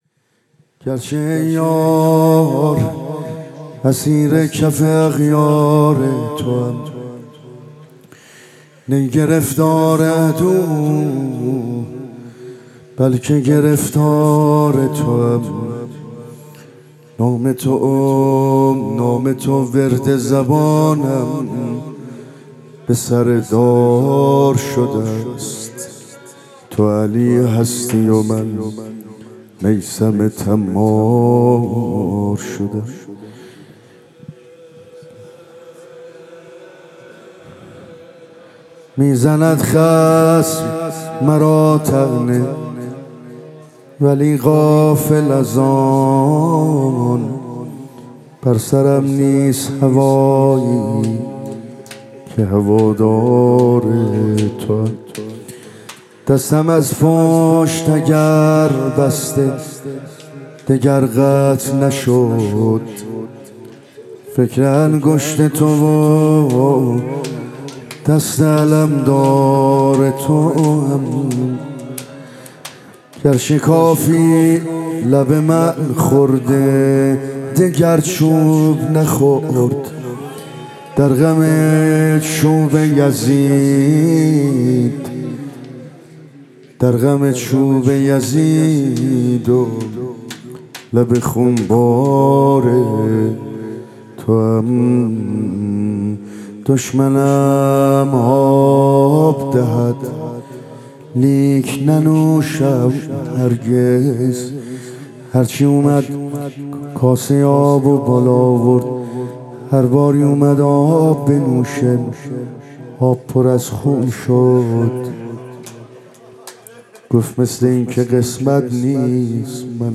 با صدای مداح اهل بیت